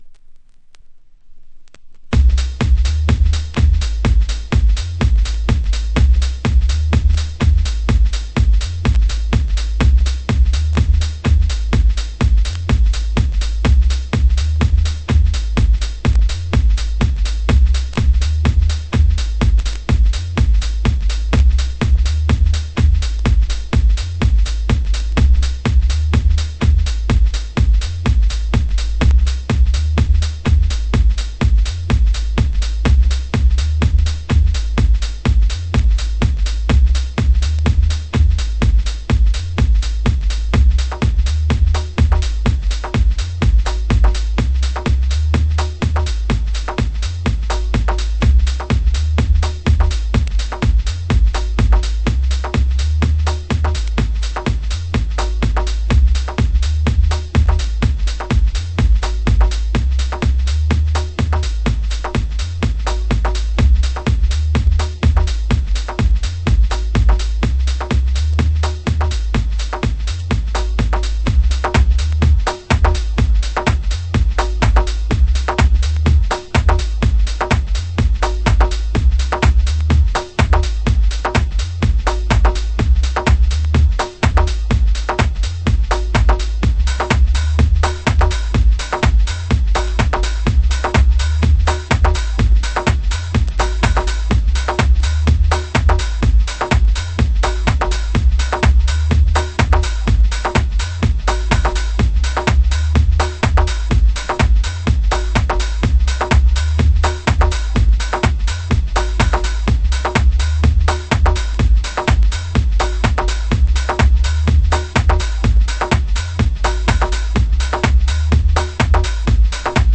HOUSE MUSIC
盤質：傷によるチリパチノイズ有